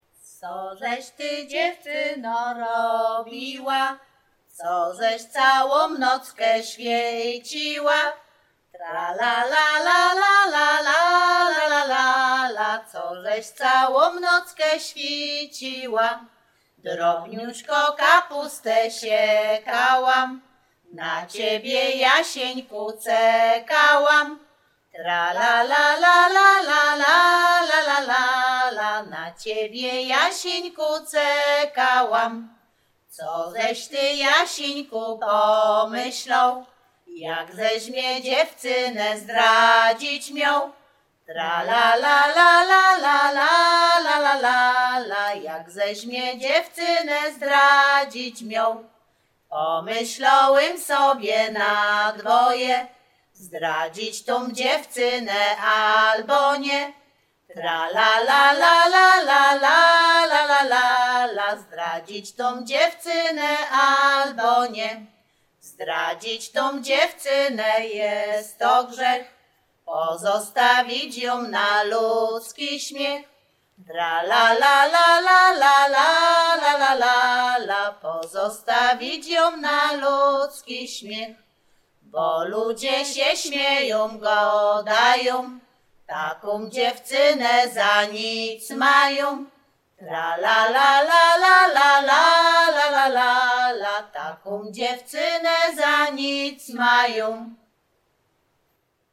Śpiewaczki z Chojnego
województwo łódzkie, powiat sieradzki, gmina Sieradz, wieś Chojne
liryczne miłosne pieśni piękne